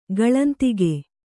♪ gaḷantige